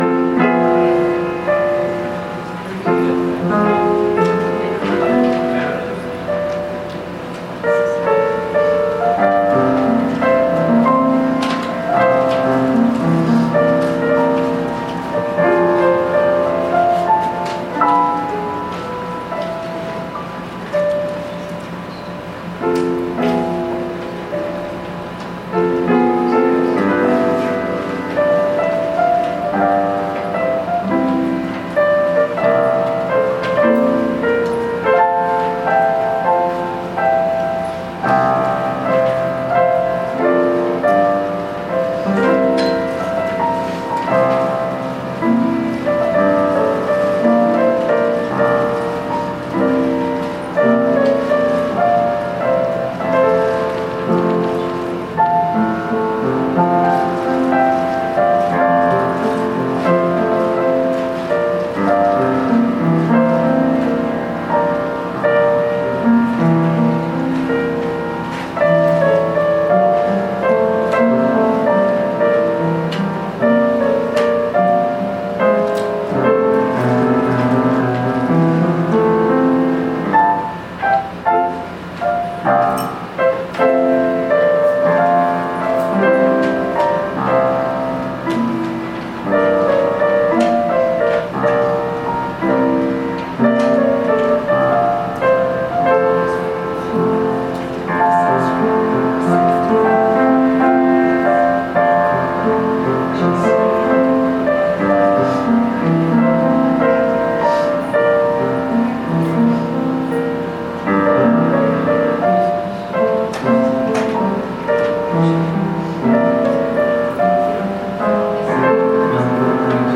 Worship Service September 2, 2018 | First Baptist Church, Malden, Massachusetts
Offertory Music with Piano and Violin.